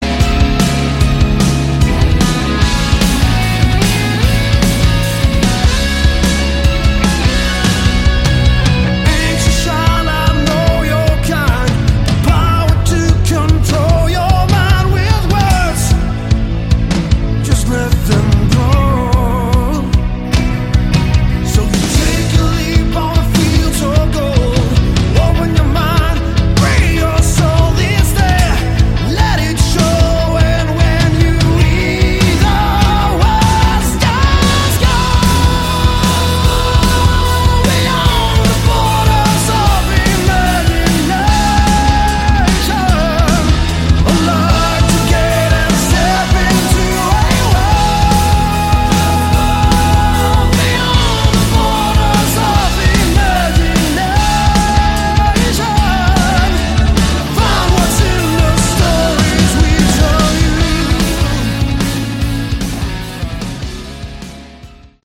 Category: Melodic Rock
keyboards, vocals
guitar, keyboards
bass
drums